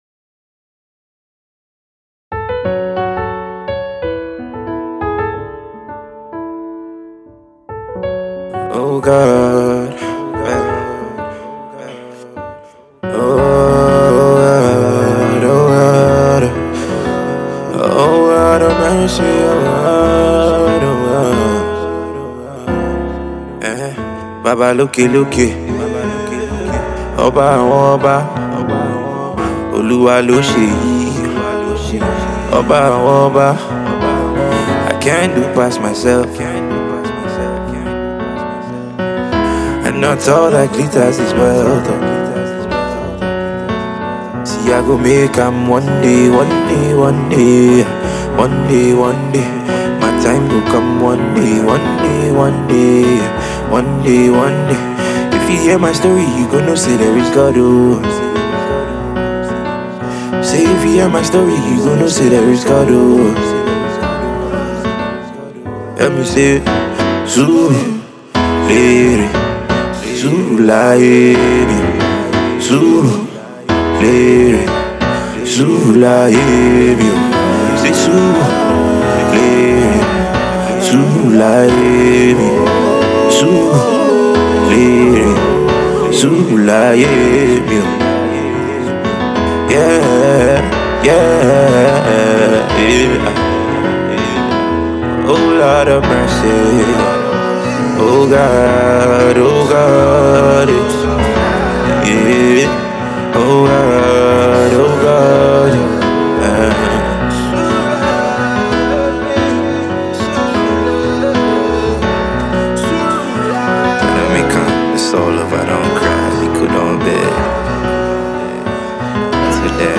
Cover
live instrumentation